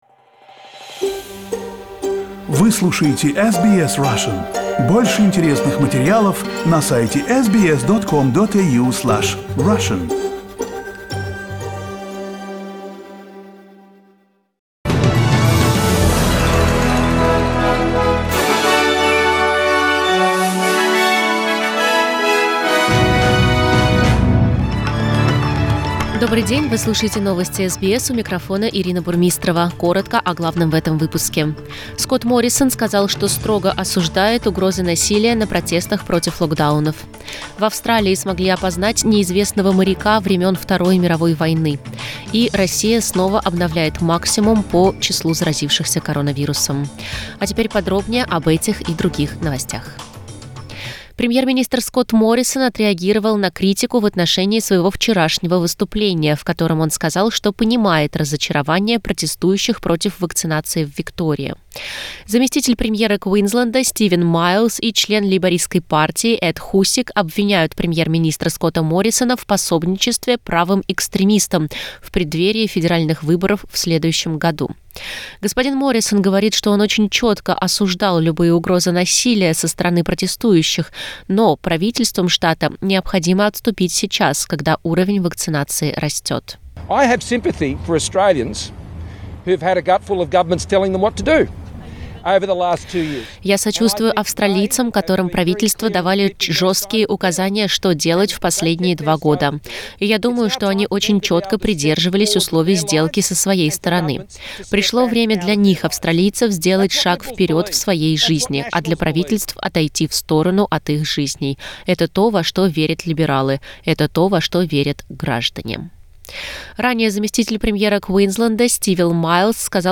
SBS news in Russian - 19.11